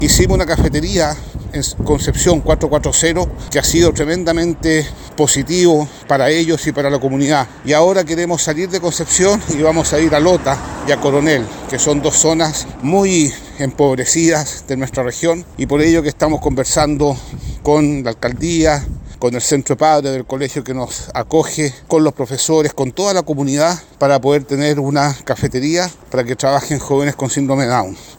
Chomalí comentó que este proyecto de cafetería busca lo mismo que dio origen a los proyectos inclusivos que ya existen.